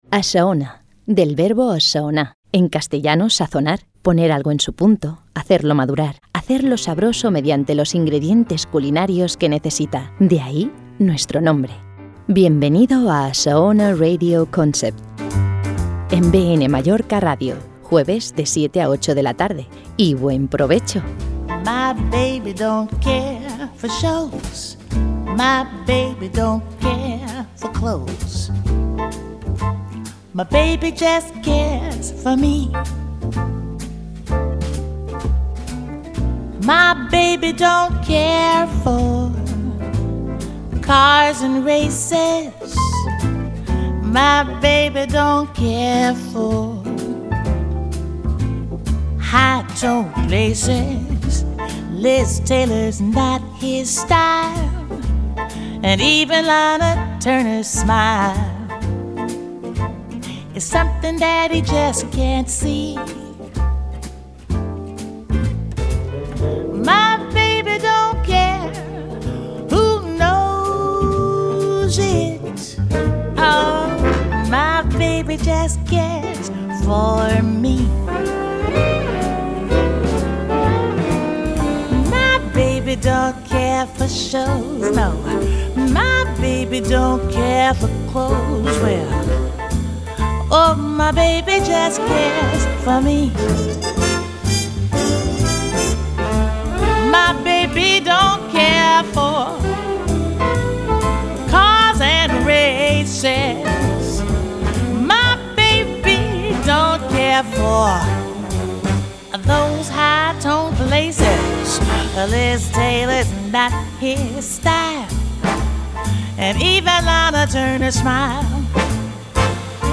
sesion-musical-patrocinada-CDicDBiU.mp3